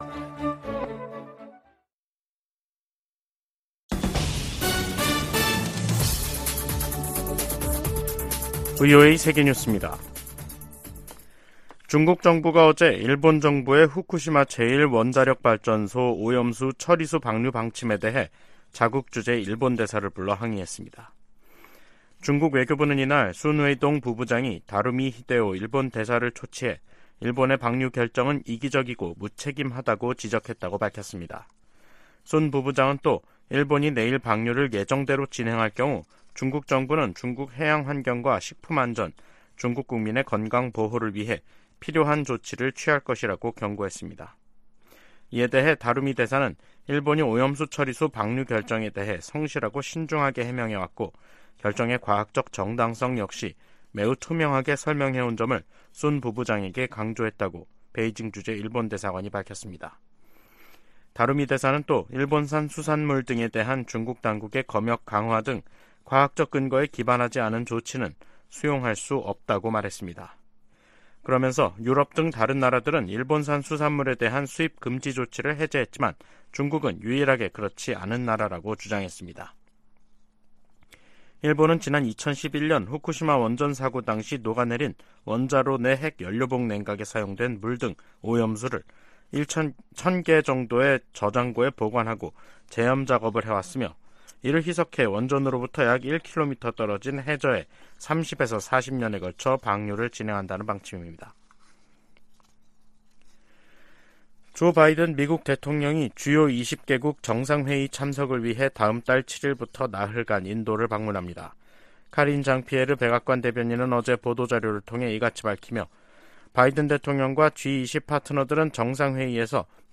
VOA 한국어 간판 뉴스 프로그램 '뉴스 투데이', 2023년 8월 23일 2부 방송입니다. 미 국무부는 탄도미사일 기술이 이용되는 북한의 모든 발사는 유엔 안보리 결의 위반임을 거듭 지적했습니다. 미 국방부가 북한의 위성 발사 통보와 관련해 한국·일본과 긴밀히 협력해 대응하겠다고 밝혔습니다. 미국 정부가 미국인의 북한 여행을 금지하는 조치를 또다시 연장했습니다.